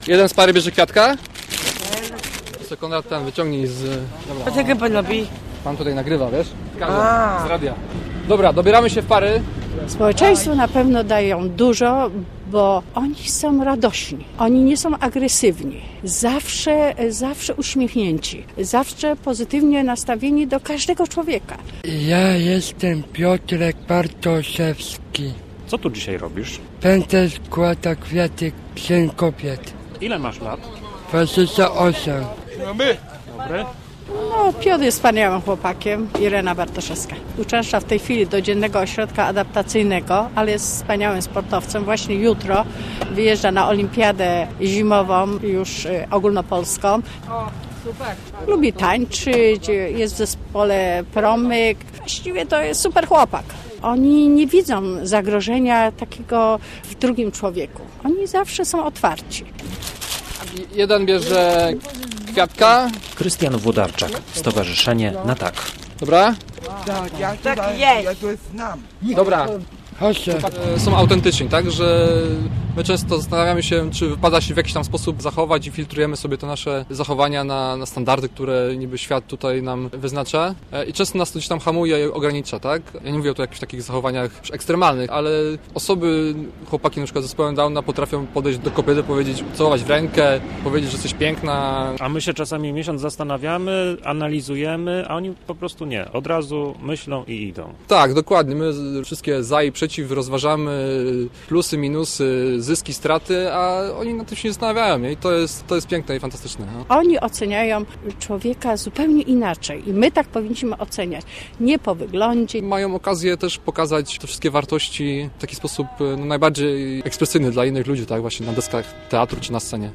Ludzie z Zespołem Downa rozdawali dziś paniom w centrum Poznania kwiaty.